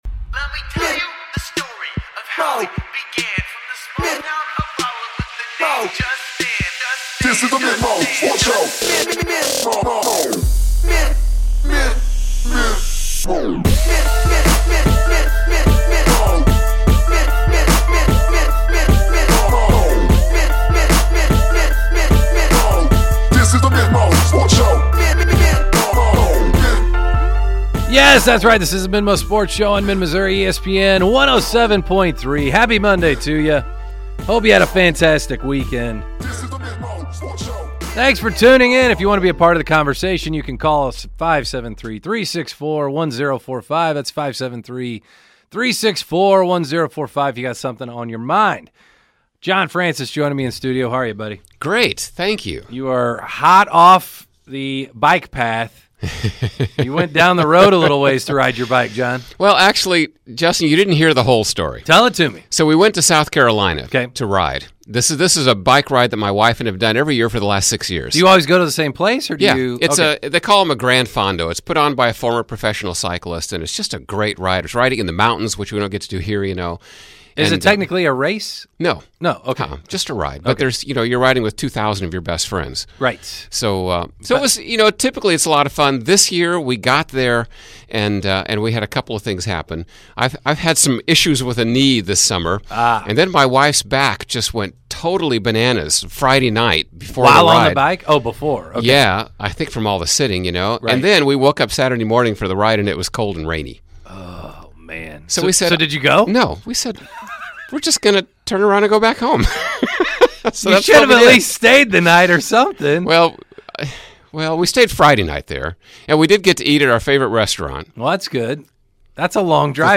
and listener phone calls.